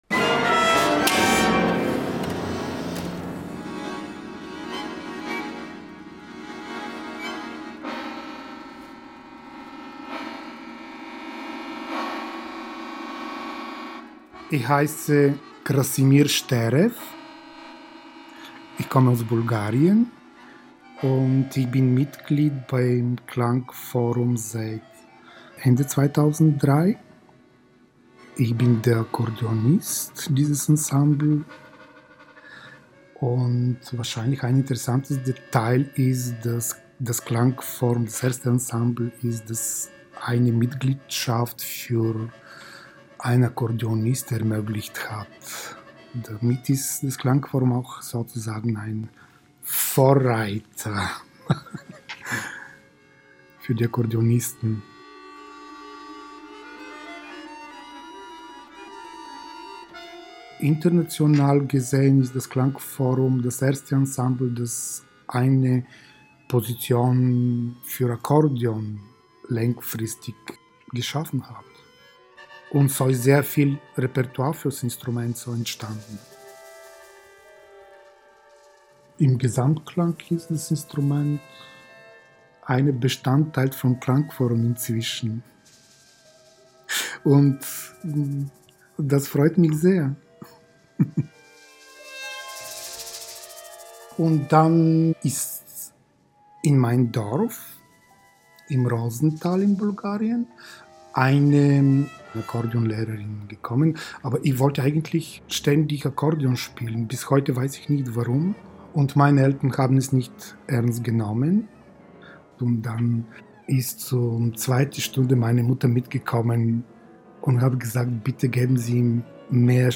Akkordeon